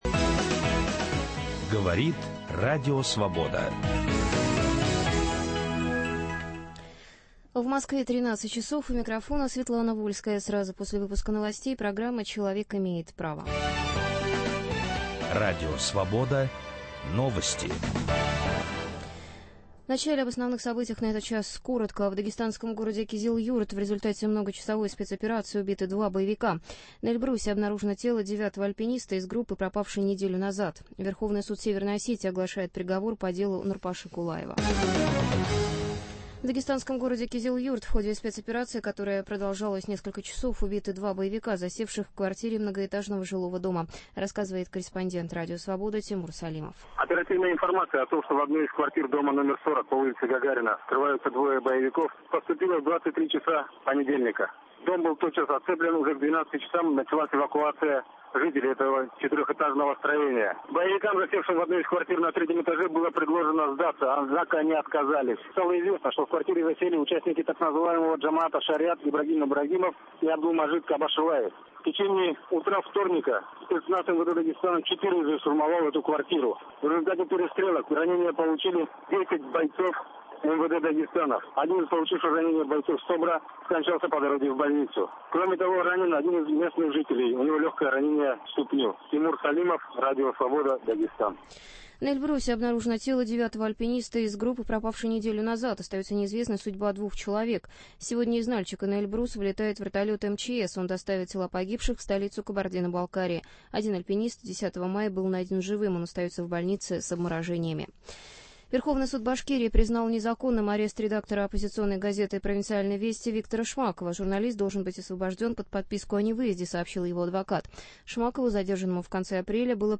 Участники передачи: председатель Московской Хельсинкской группы Людмила Алексеева